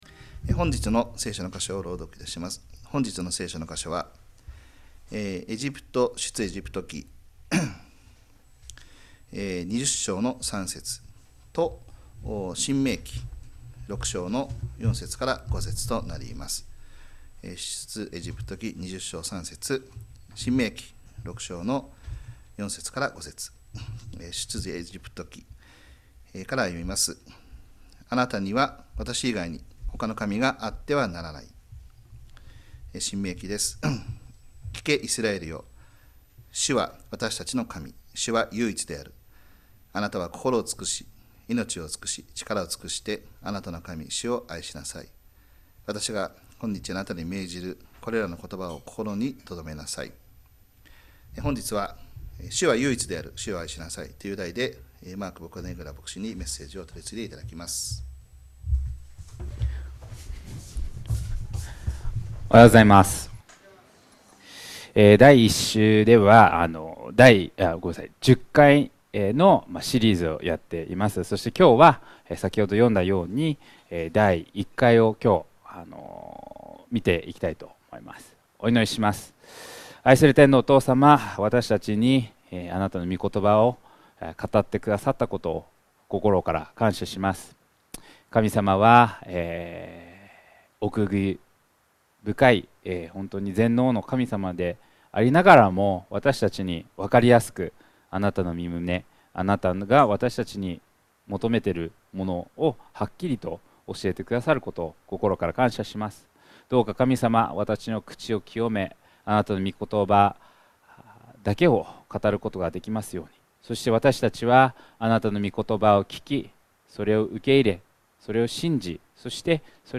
2025年5月4日礼拝 説教 「主は唯一である。主を愛しなさい」 – 海浜幕張めぐみ教会 – Kaihin Makuhari Grace Church